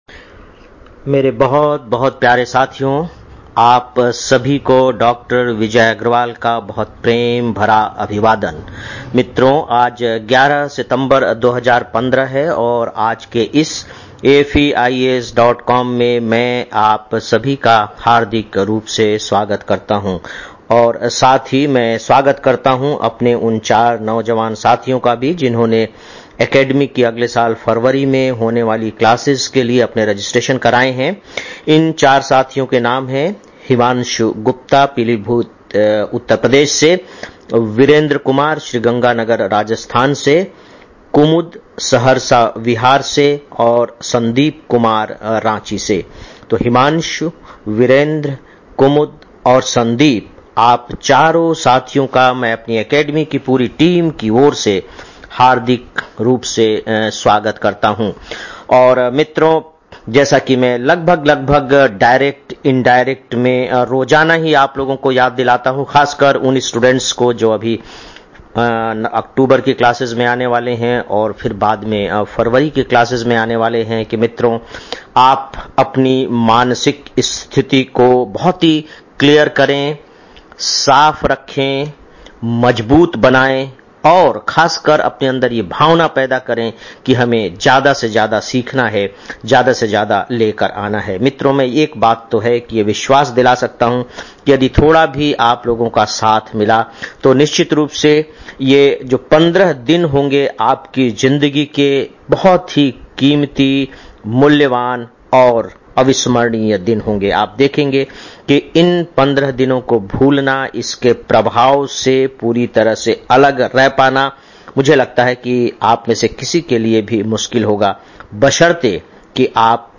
11-09-15 (Daily Audio Lecture) - AFEIAS